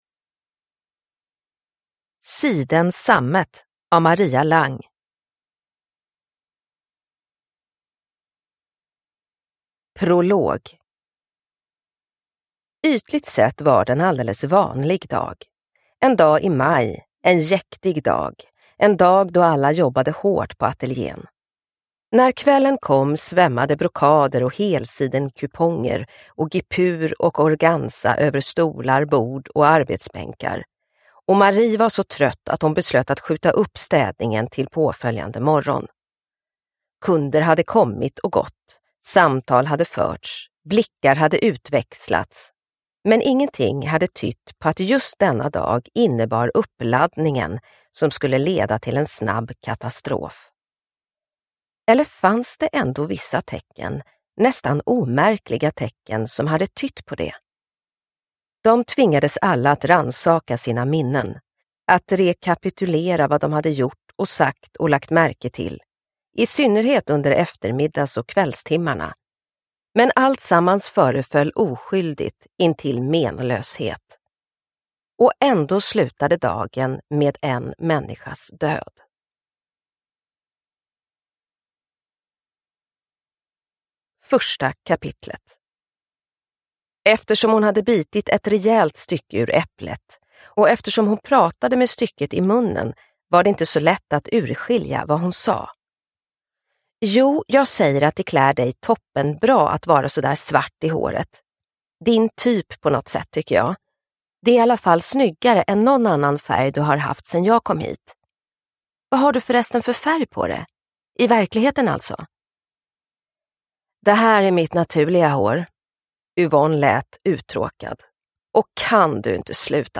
Siden sammet – Ljudbok – Laddas ner